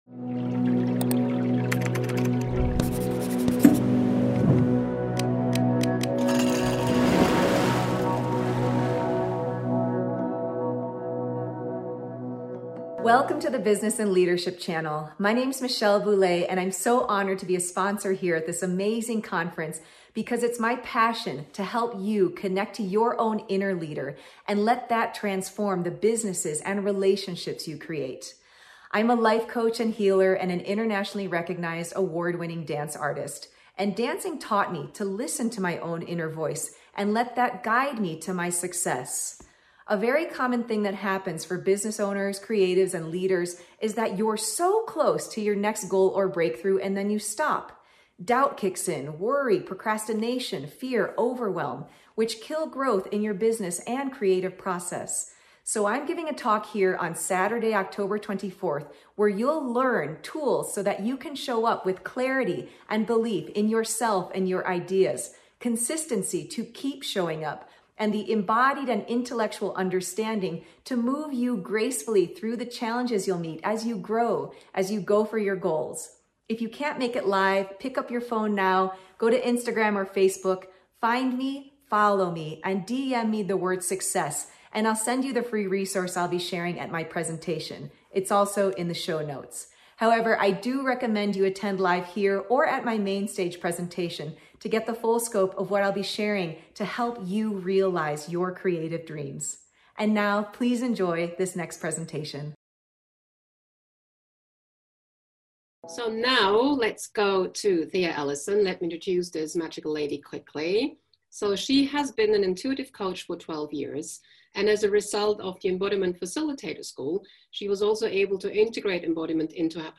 Likely soothing